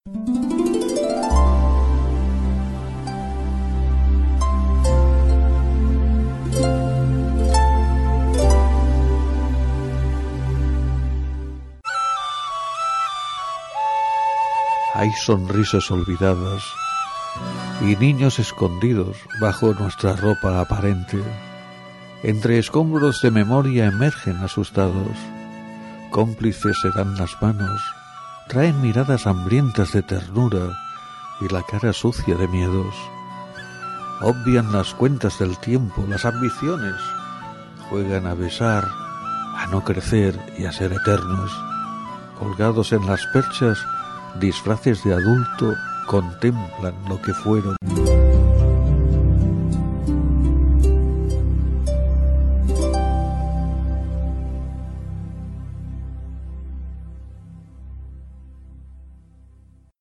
Lectura d'un poema
FM